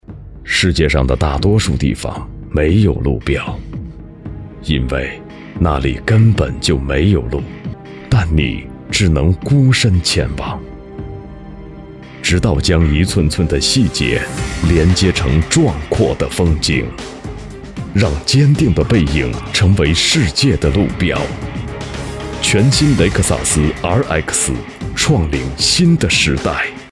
广告男44号（雷克萨斯）
激情力度 品牌广告